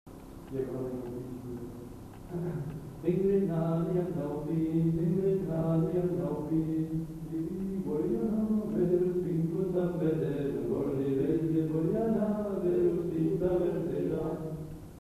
Chants et airs à danser
enquêtes sonores